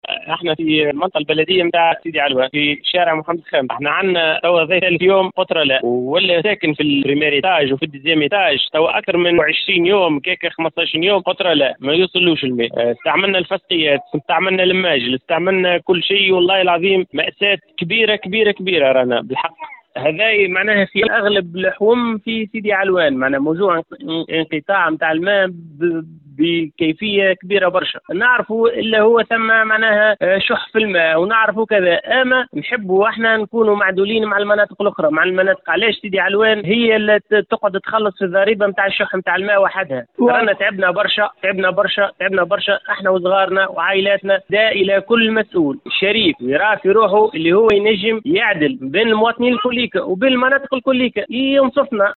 وجه عدد من أهالي المنطقة البلدية سيدي علوان نداء عاجلا عبر إذاعة ”ام اف ام” للسلط الجهوية بسبب الانقطاع المتواصل للماء الصالح للشراب .